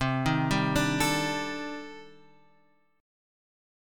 C Minor 6th Add 9th
Cm6add9 chord {8 6 5 7 x 5} chord